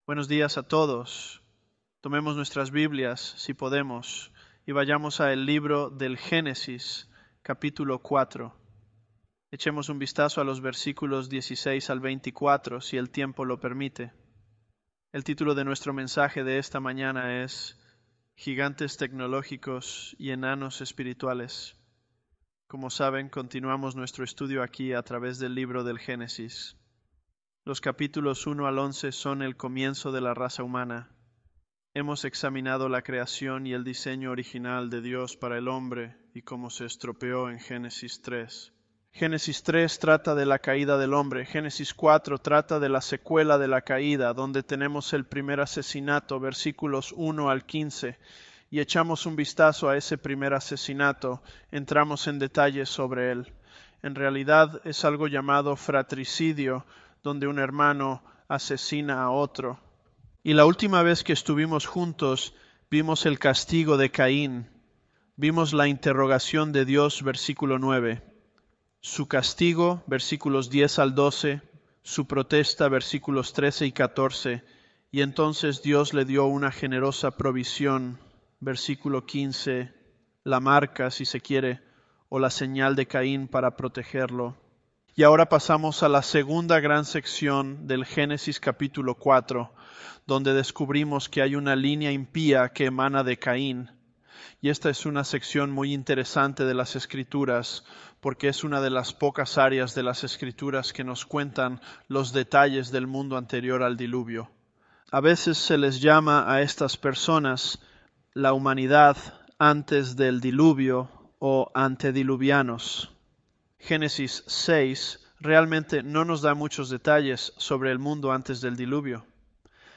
Sermons
ElevenLabs_Genesis-Spanish018.mp3